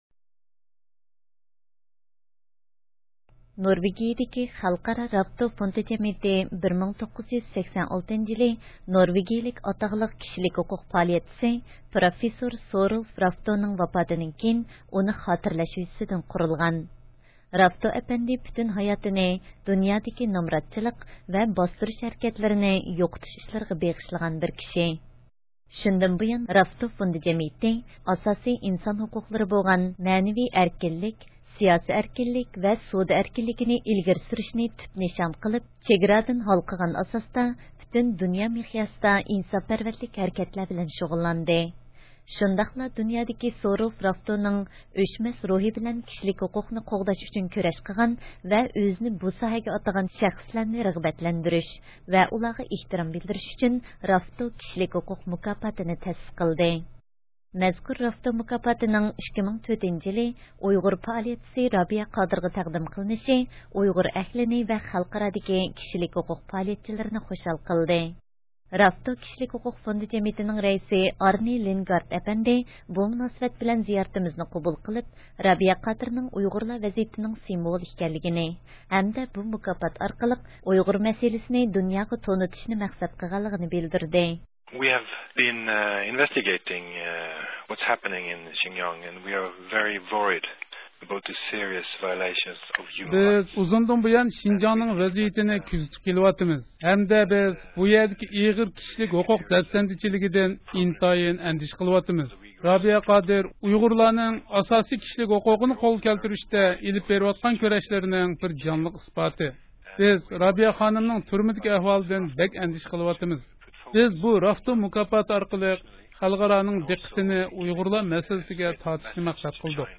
رابىيە قادىرنىڭ رافتو مۇكاپاتىغا ئېرىشكەنلىكى ھەققىدە زىيارەت – ئۇيغۇر مىللى ھەركىتى